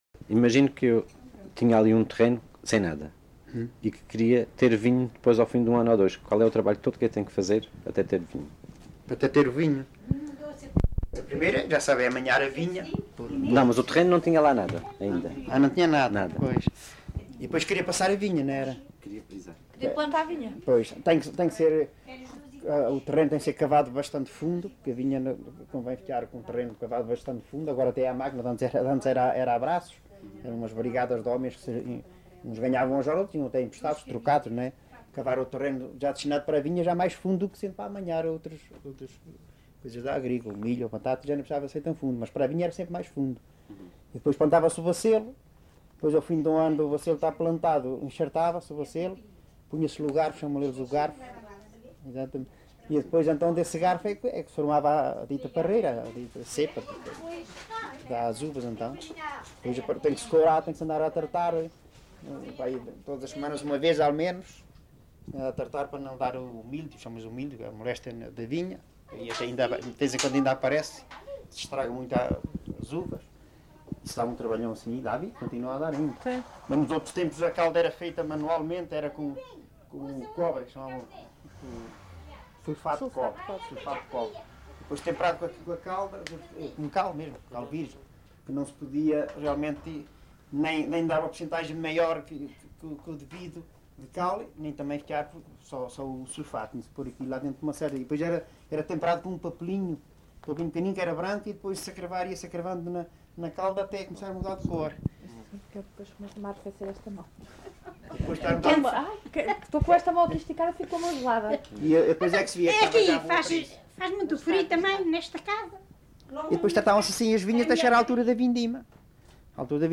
LocalidadeMoita do Martinho (Batalha, Leiria)